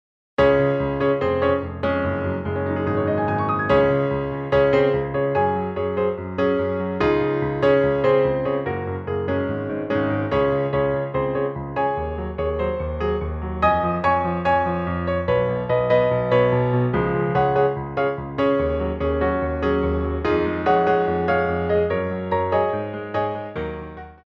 Frappés
4/4 (16x8)